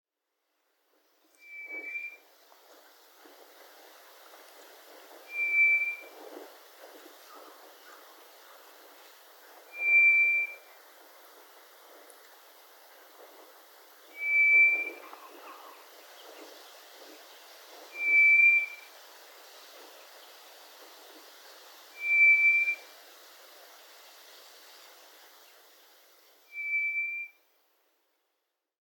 トラツグミは繁殖期には夜間に「ヒョー」「ヒー」と口笛のような不気味な声でさえずるため平安時代には妖怪「ぬえ」と恐れられたという。今回のように真っ昼間に聞けるのは珍しい。
【録音⑤】 トラツグミ